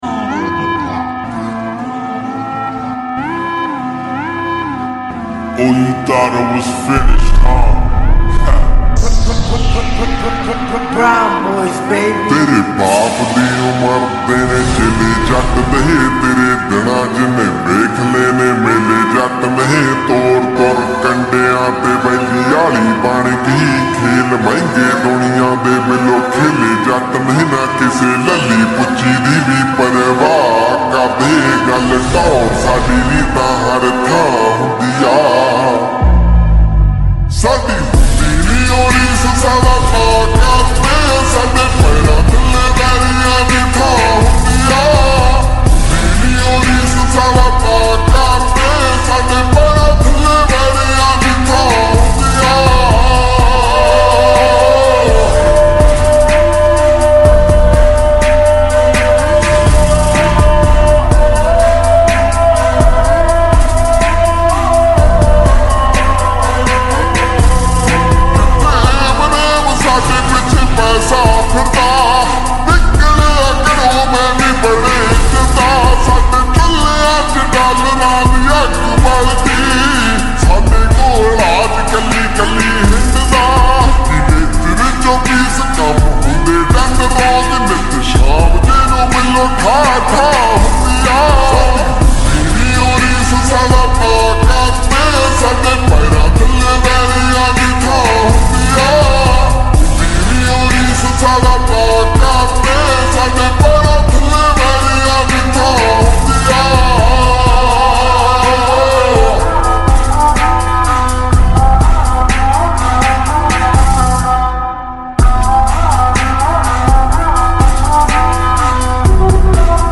Lo-Fi Remix